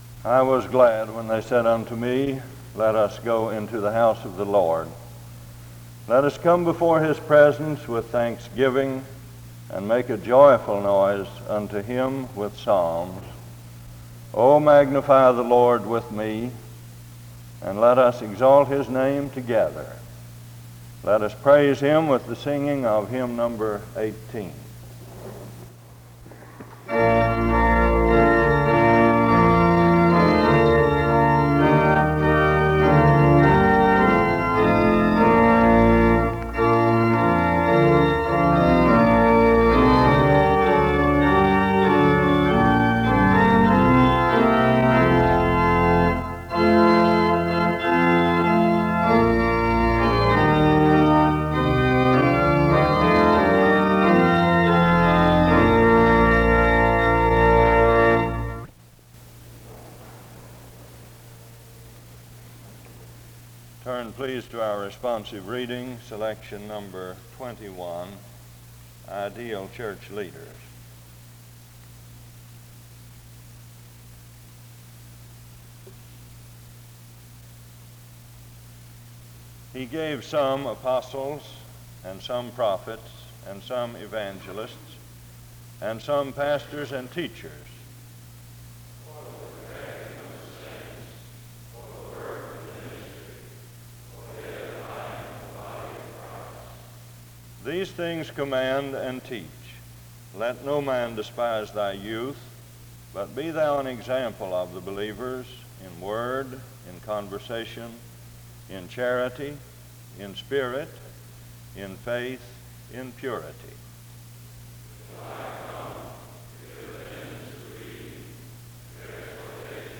In this chapel service
SEBTS Chapel and Special Event Recordings SEBTS Chapel and Special Event Recordings